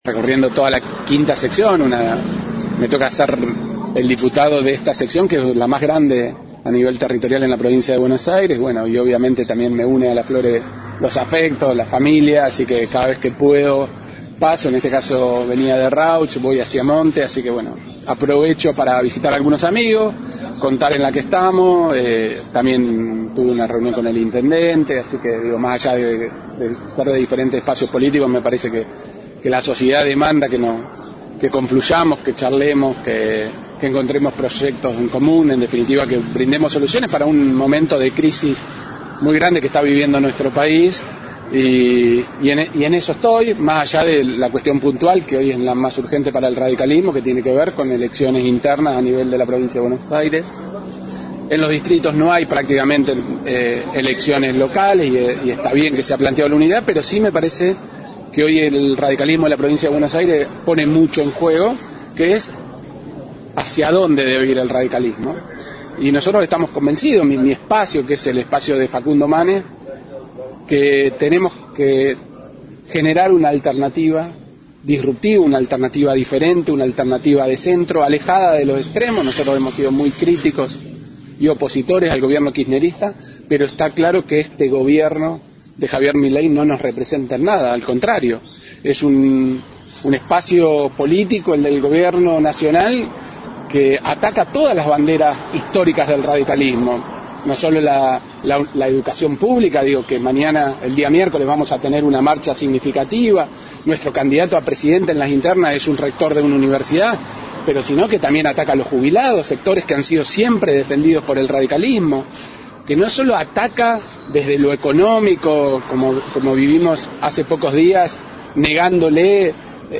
LA RADIO, entrevistó a distintos dirigentes por las internas del radicalismo que se dirime mañana domingo desde las 8 hs. hasta las 18 hs.. A principios de semana llegó desde Tandil el legislador provincial Matías Civale que acompaña la lista 15 que encabeza como delegado titular del comité nacional Federico Storani.